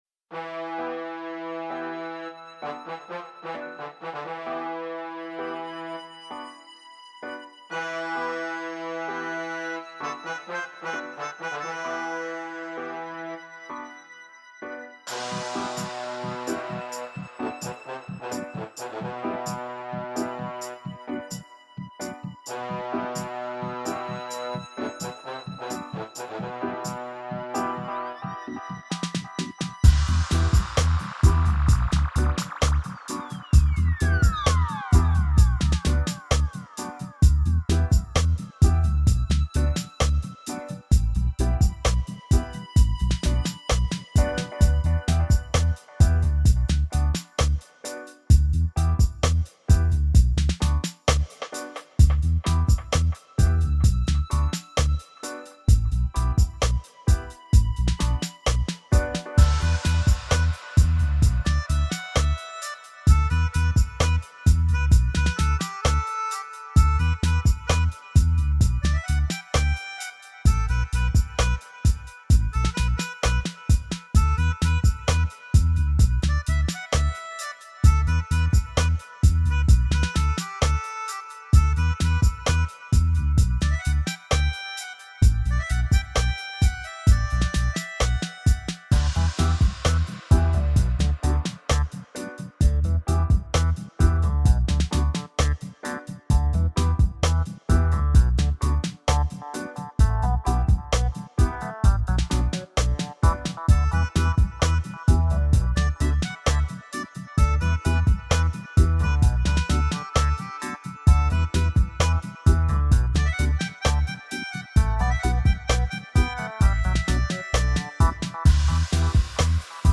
DUB